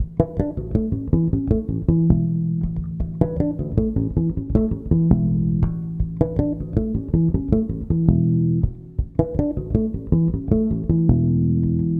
低音融合1
描述：适用于许多流派的无品类爵士贝斯的旋律
Tag: 80 bpm Fusion Loops Bass Guitar Loops 2.02 MB wav Key : E